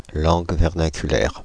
Ääntäminen
Synonyymit parler Ääntäminen France (Île-de-France): IPA: /lɑ̃g vɛʁ.na.ky.lɛʁ/ Haettu sana löytyi näillä lähdekielillä: ranska Käännöksiä ei löytynyt valitulle kohdekielelle.